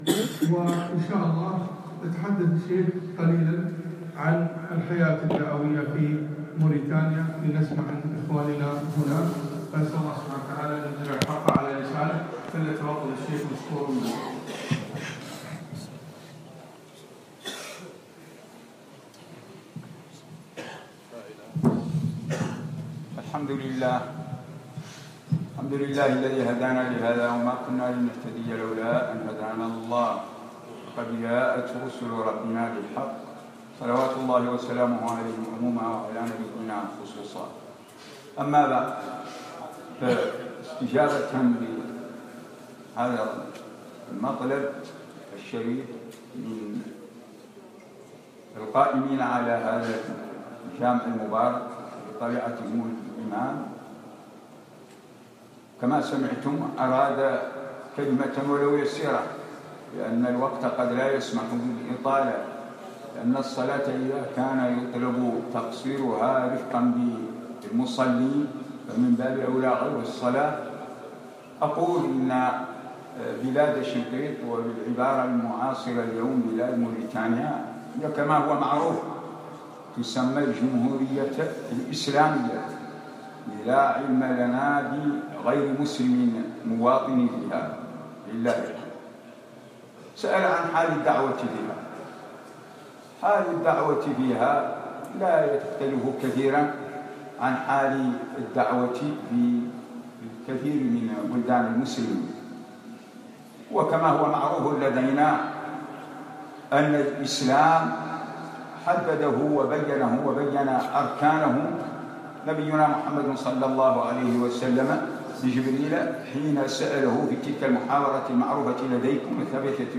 بعد صلاة الجمعة 4 3 2016 مسجد عبدالله بن الأرقم الجهراء
الدعوة في موريتانيا - كلمة